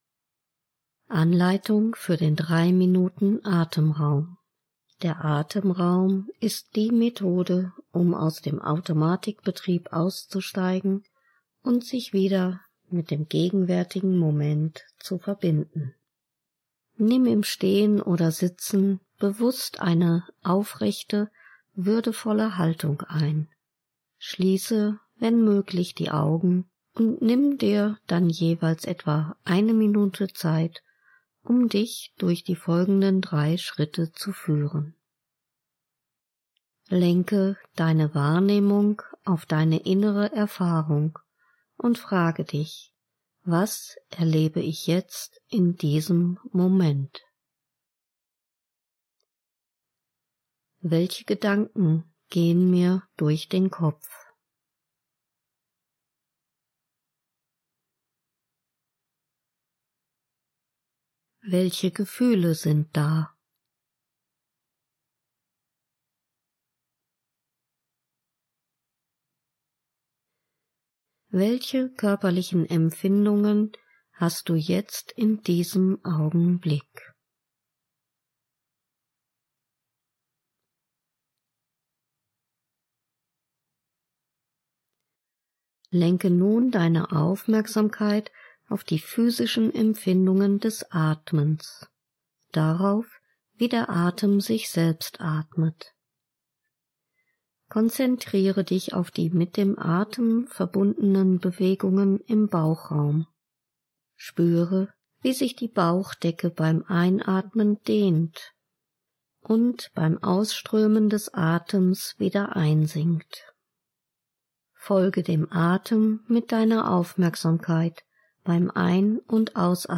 Anleitung für den 3-Minuten-AtemraumHerunterladen